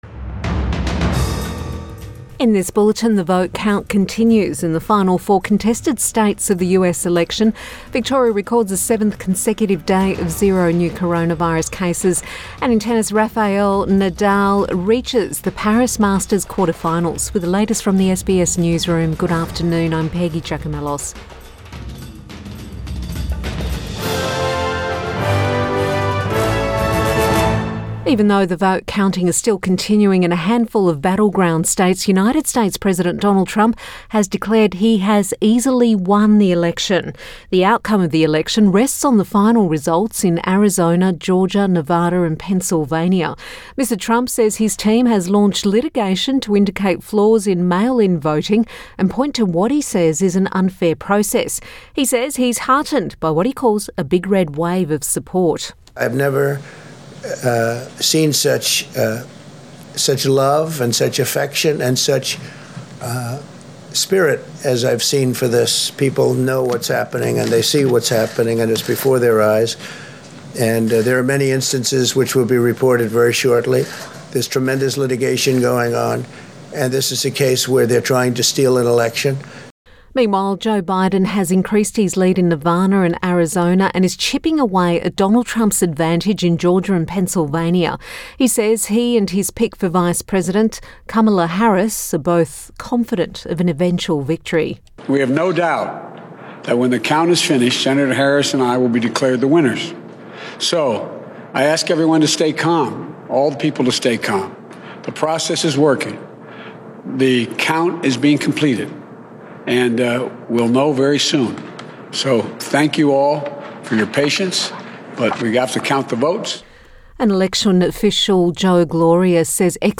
Midday bulletin 6 November 2020